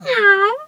cat_2_meow_07.wav